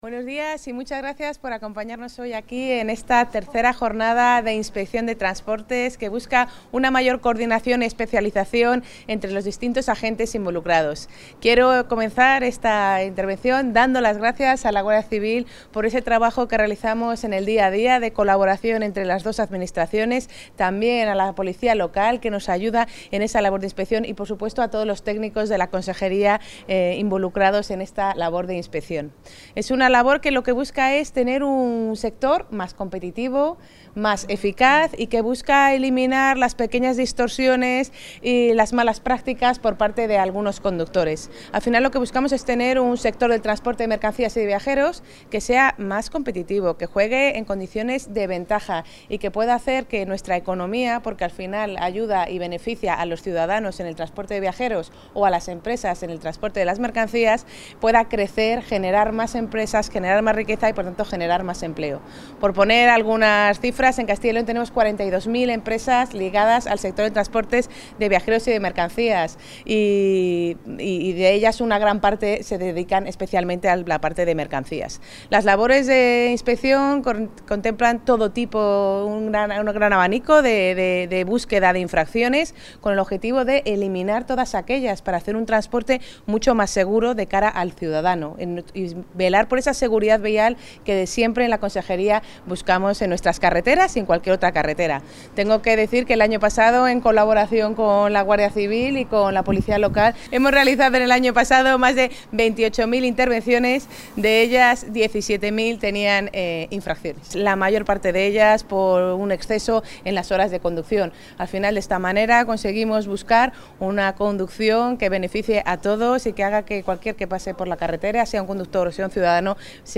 Valladolid ha acogido la III Jornada de Coordinación y Especialización en Inspección de Transportes que, organizada por la Junta...
Intervención de la consejera.